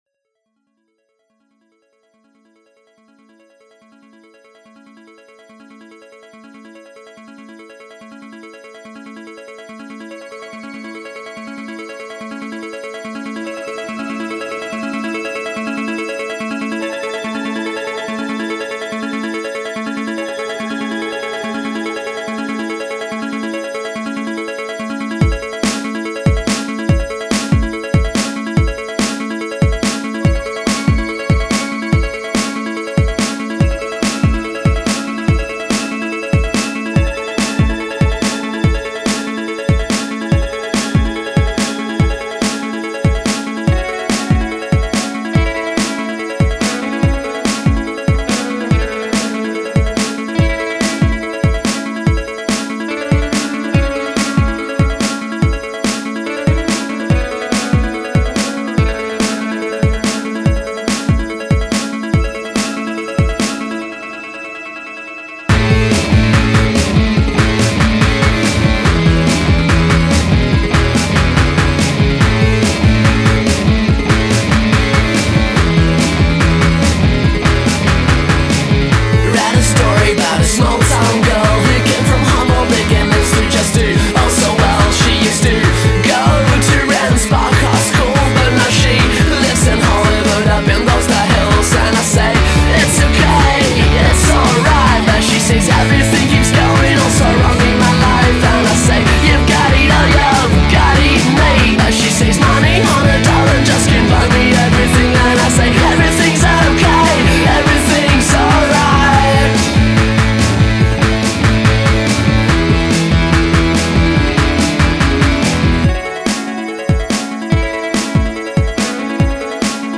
Christmas Carol version